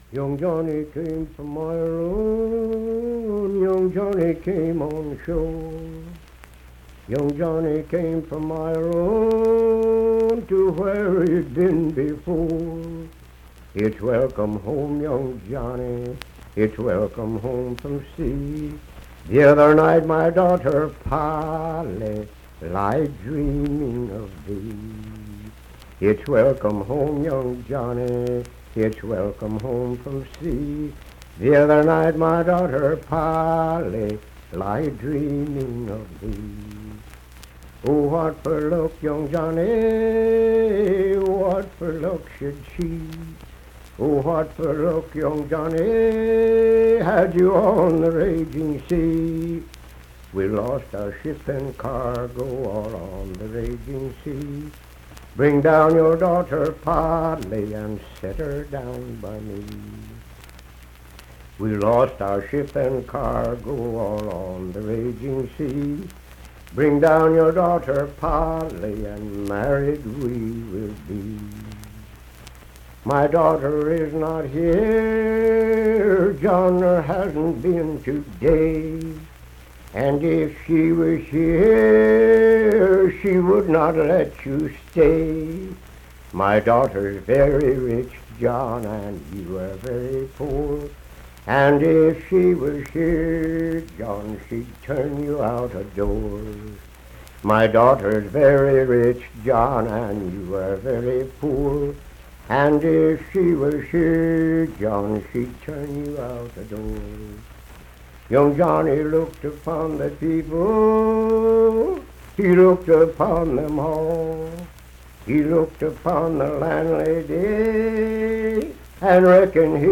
Unaccompanied vocal music performance
Verse-refrain 7d(6w/R).
Voice (sung)
Birch River (W. Va.), Nicholas County (W. Va.)